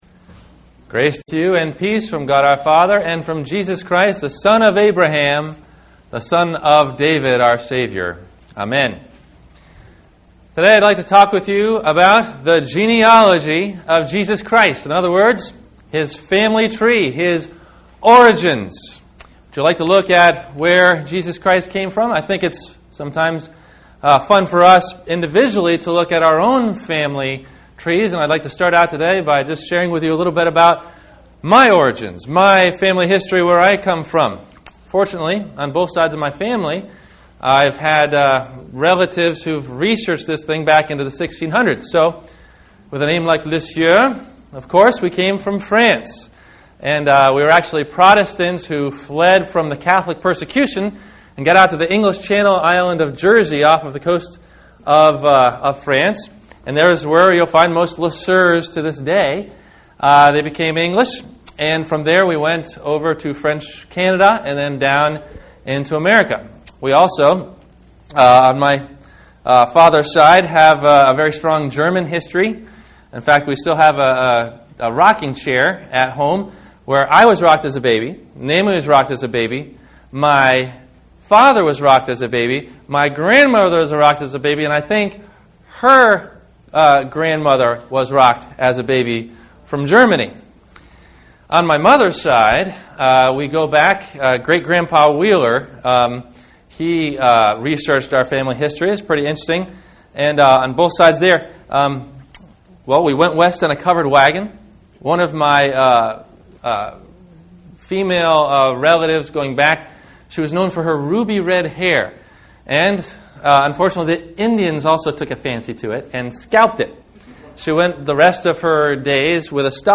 The Genealogy of Jesus Christ – Sermon – January 03 2010